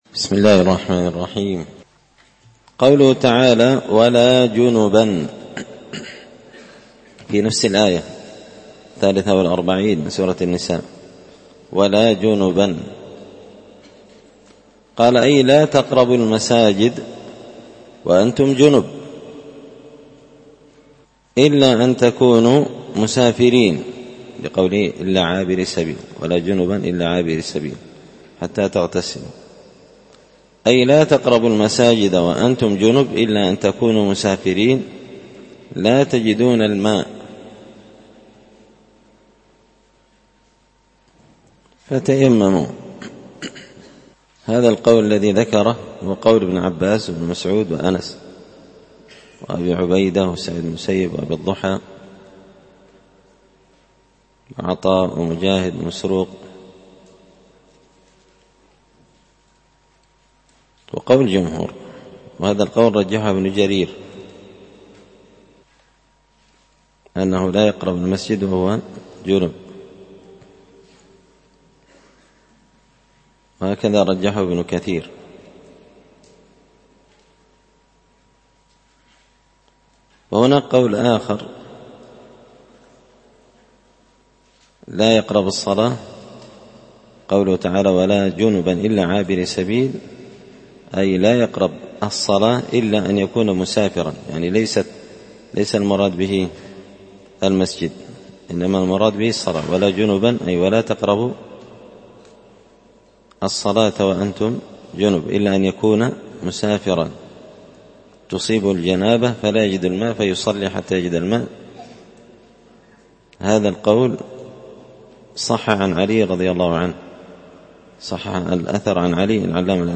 تفسير المشكل من غريب القرآن ـ الدرس 91
مسجد الفرقان _قشن_المهرة_اليمن